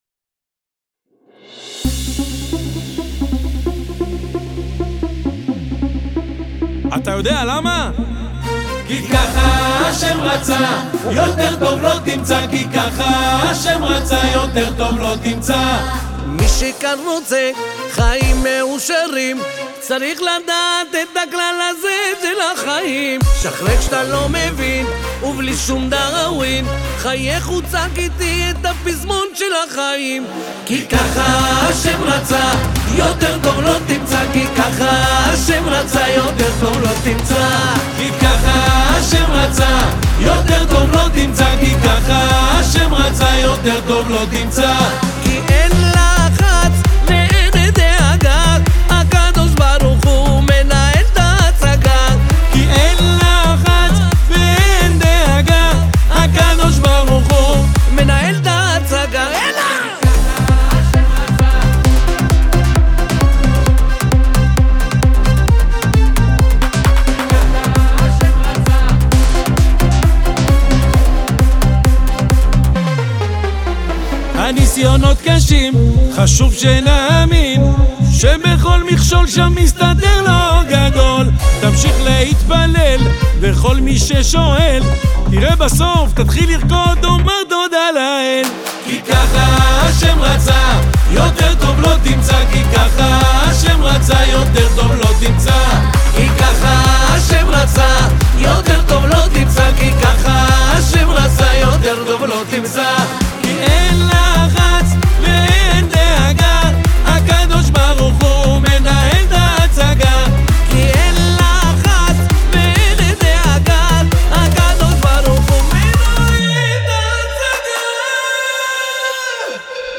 עטופה במוזיקה עכשווית וסוחפת.
תוספות שירה וקולות
נגינה וקלידים